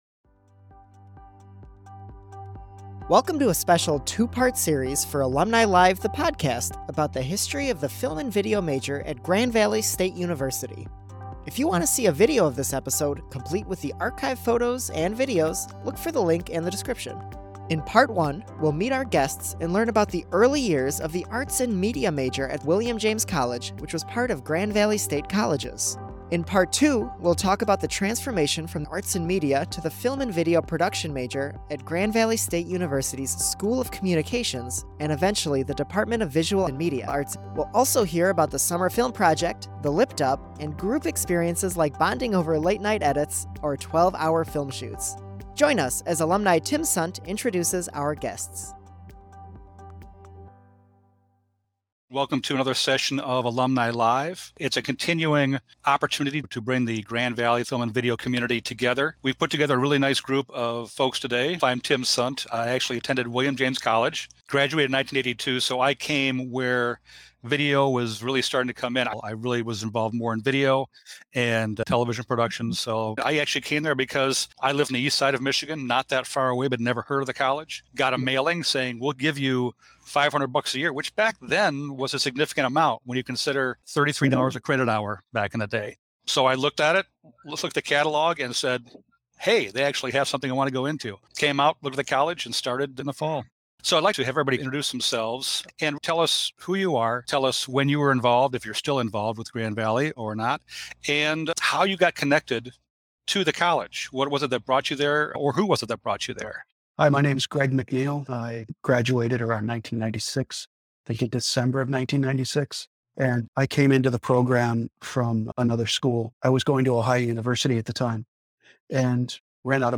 Alumni and faculty talk about the 40 plus year history of the Grand Valley Film & Video Production major and what has made it unique. Part 1 explores the early years of the Arts and Media major at William James College, which was part of Grand Valley State Colleges.